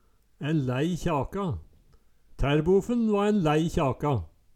Høyr på uttala Ordklasse: Uttrykk Kategori: Uttrykk Attende til søk